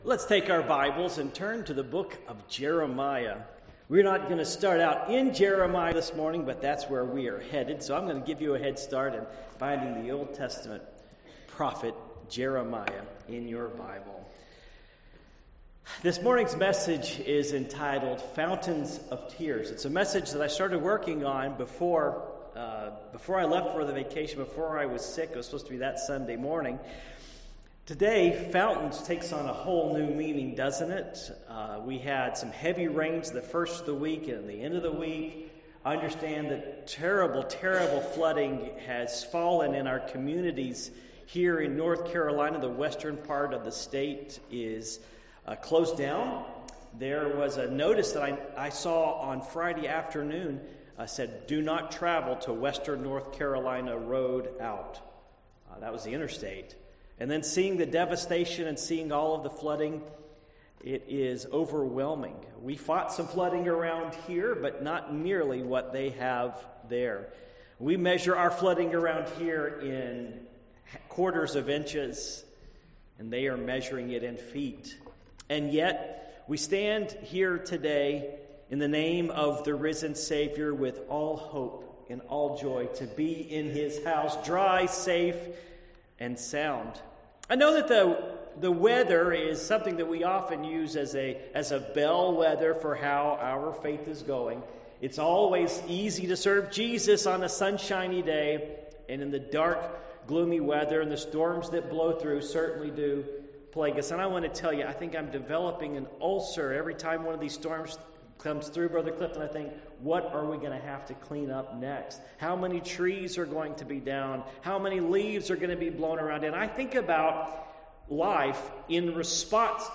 Passage: Jeremiah 9:1 Service Type: Morning Worship